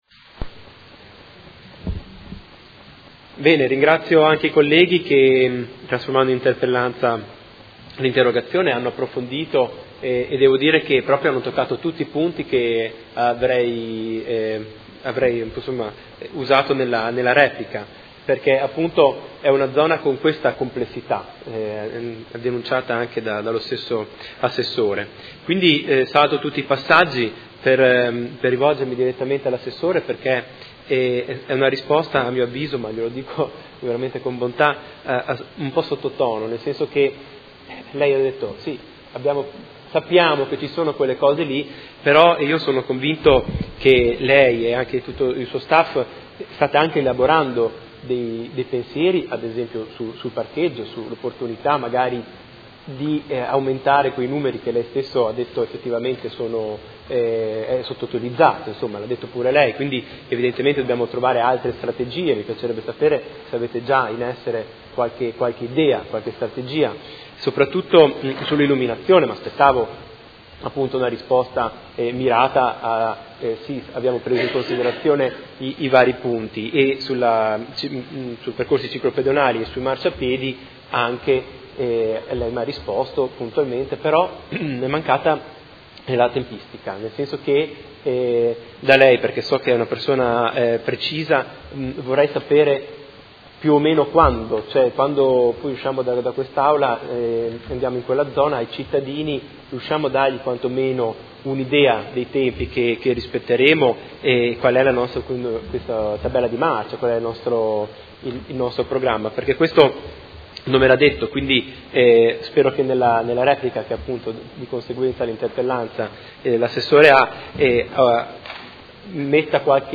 Seduta del 26/10/2017. Dibattito su interrogazione del Gruppo Consiliare Per Me Modena avente per oggetto: Viabilità zona San Pio X